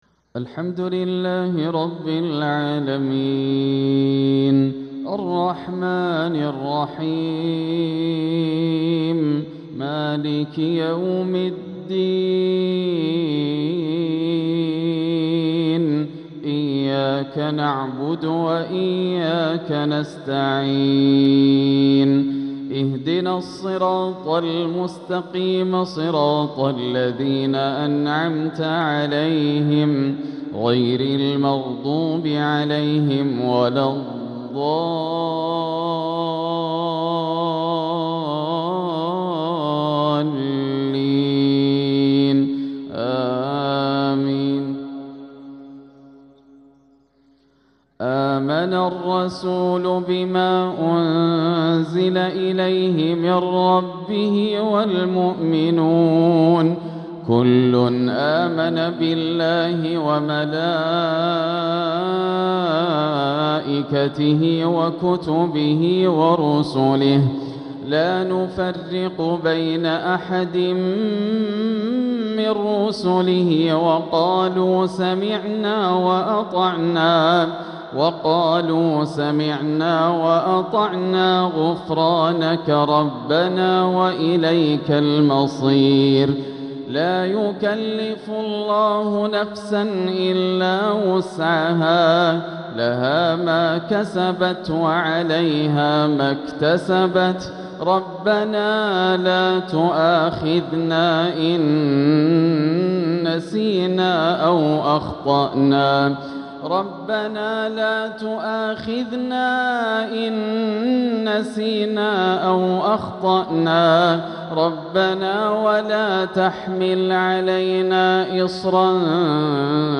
تلاوة لآخر سورتي البقرة والصافات | مغرب الثلاثاء 4-2-1447هـ > عام 1447 > الفروض - تلاوات ياسر الدوسري